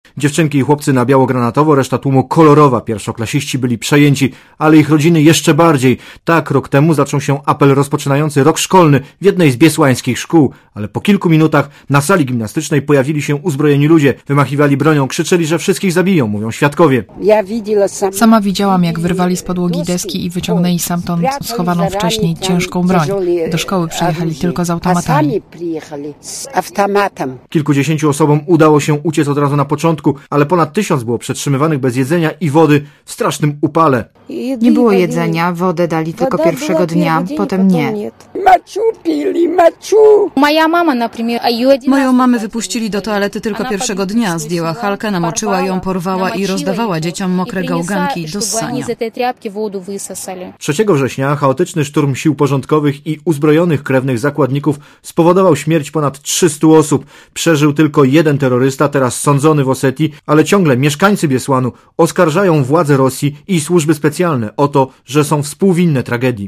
Relacja reporterów Radia ZET Oceń jakość naszego artykułu: Twoja opinia pozwala nam tworzyć lepsze treści.